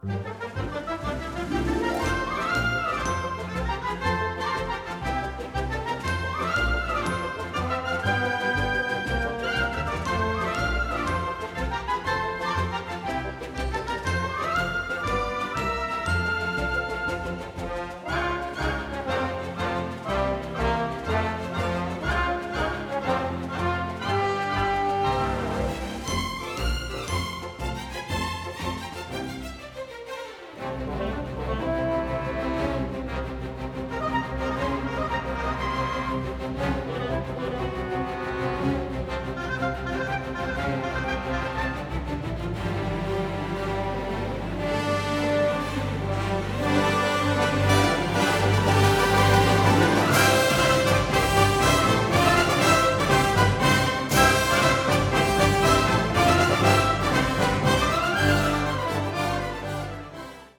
Recorded at CTS Studios in London